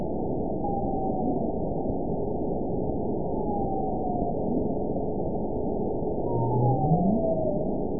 event 922306 date 12/29/24 time 07:48:36 GMT (5 months, 3 weeks ago) score 9.18 location TSS-AB04 detected by nrw target species NRW annotations +NRW Spectrogram: Frequency (kHz) vs. Time (s) audio not available .wav